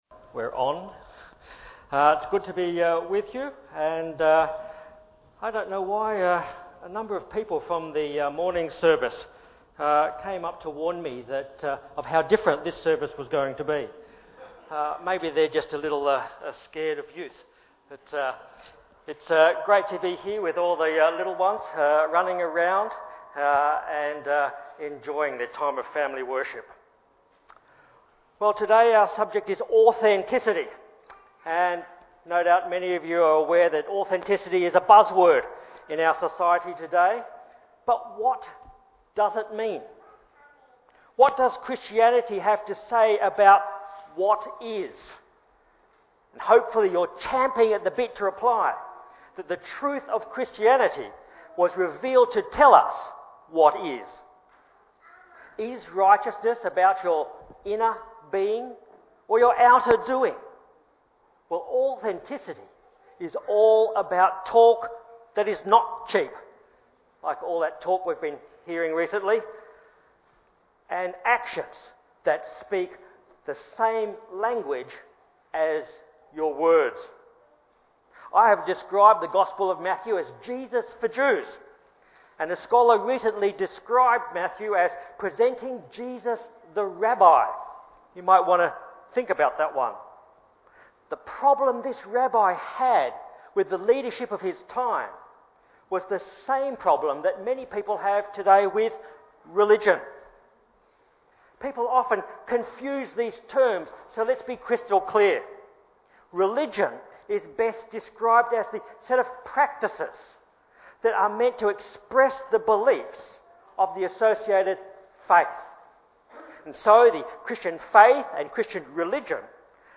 Series: Guest Preachers